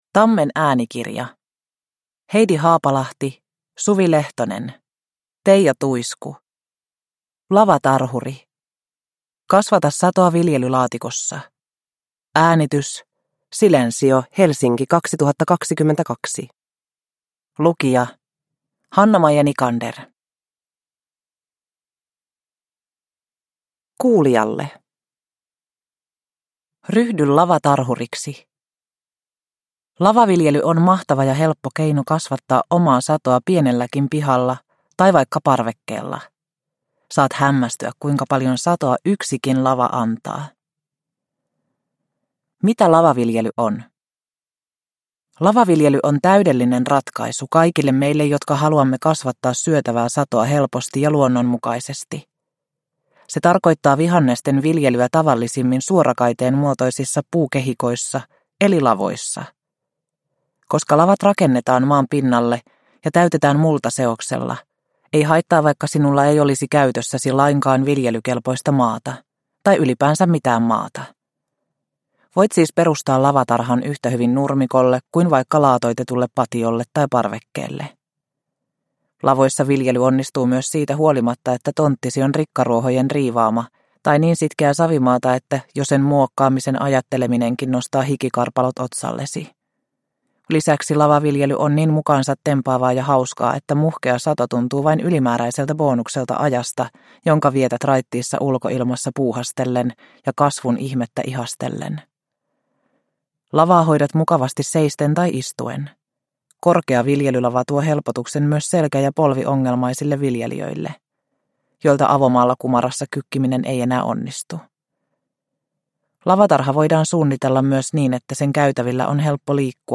Lavatarhuri – Ljudbok – Laddas ner